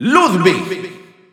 Announcer pronouncing Ludwig in Spanish.
Ludwig_Spanish_Announcer_SSBU.wav